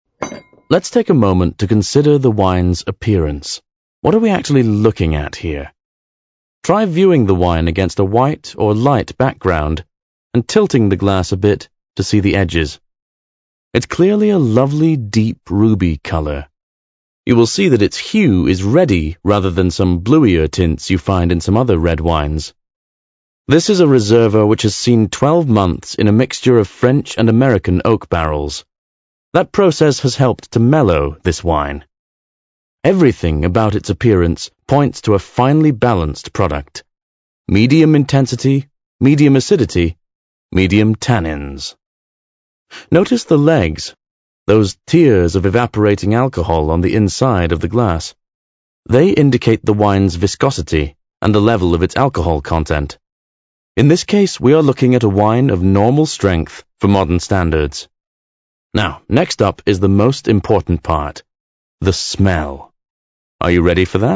• the power of audio (ASMR + Voice Acting + Soundscapes)